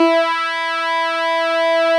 snes_synth_052.wav